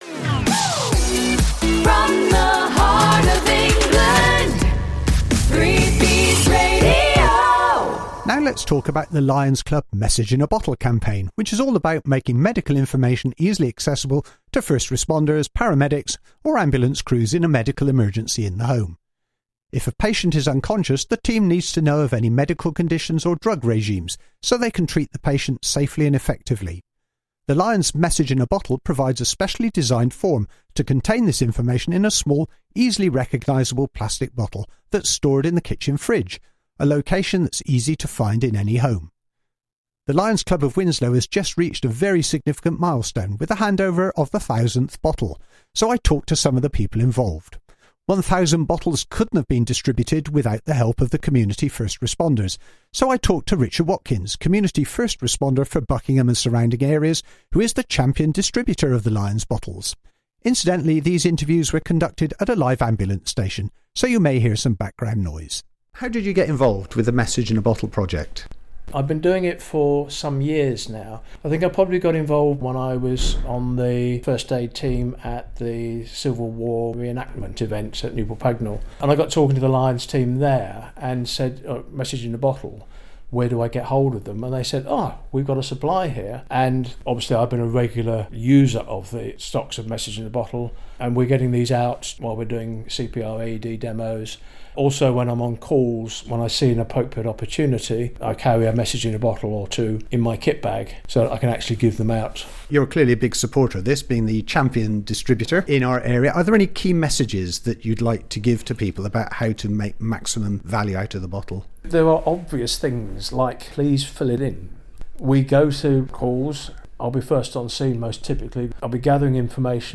Radio interview explaining Message in a Bottle
3Bs-Radio-Message-in-a-Bottle-Interviews-Segment.mp3